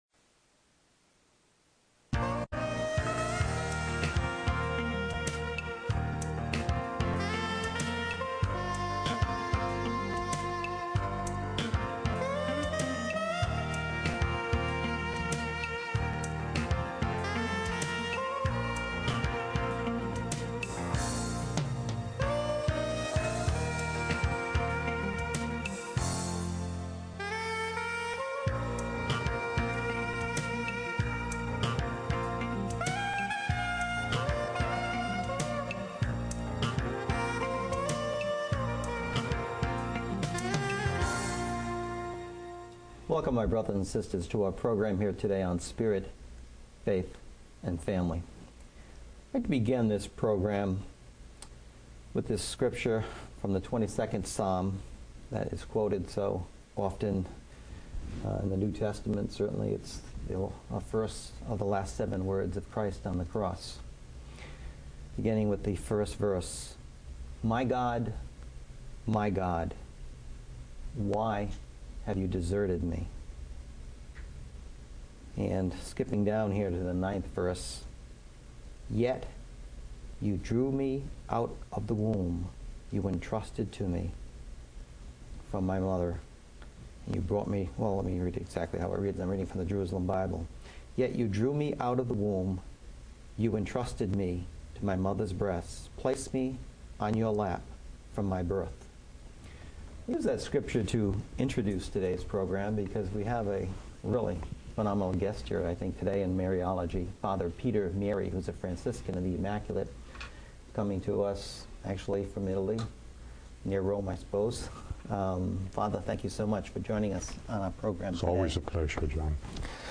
talk on Mary and Her role on Good Friday